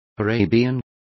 Complete with pronunciation of the translation of Arabian.